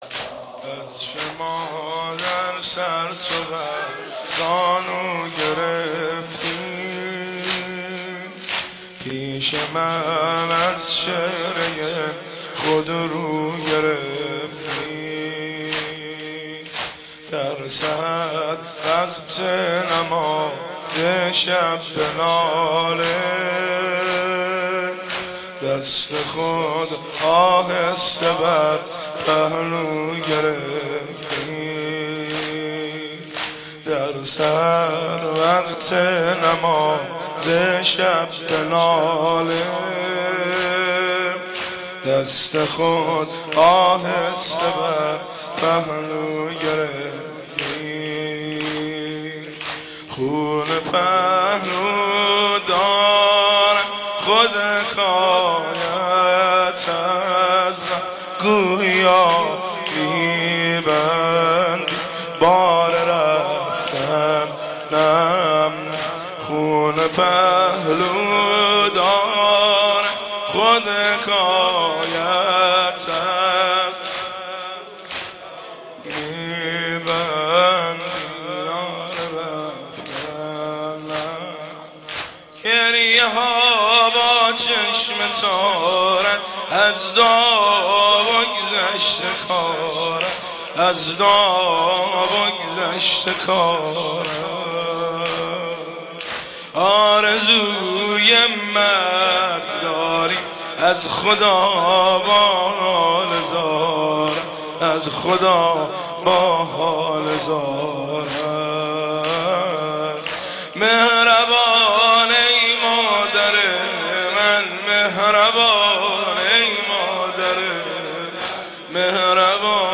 عزاداری و مدیحه‌سرایی در رثای مقام شامخ بانوی دو عالم، حضرت فاطمه زهرا (س) در ادامه قابل بهره برداری است.
مداحی